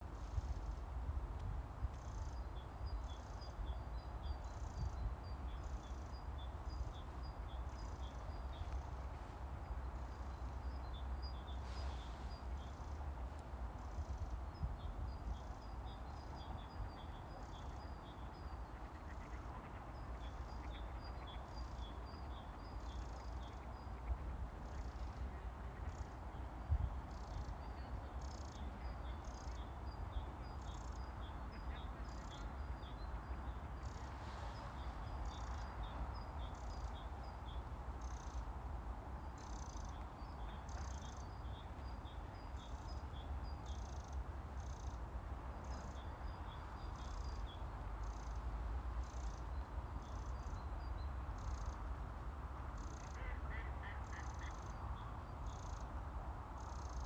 крапивник, Troglodytes troglodytes
СтатусСлышен голос, крики